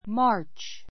March 小 A1 mɑ́ː r tʃ マ ー チ 名詞 3月 ⦣ Mar.